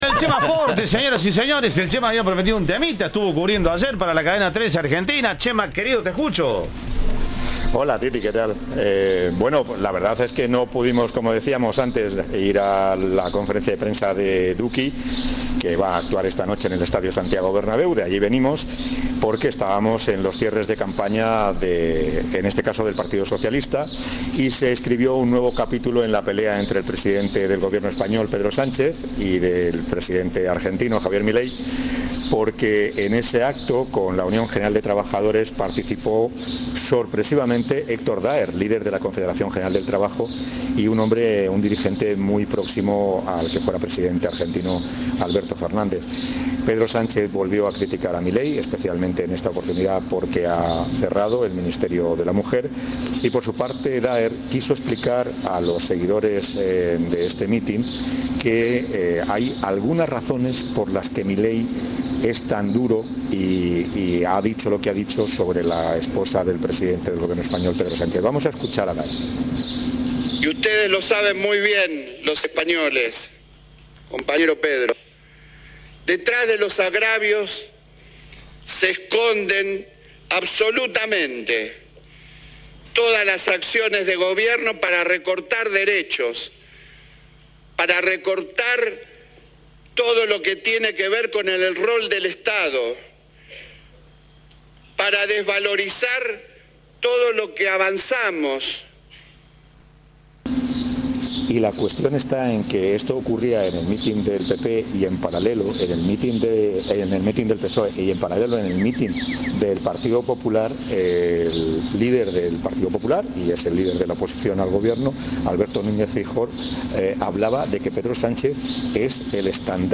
Informe
desde España.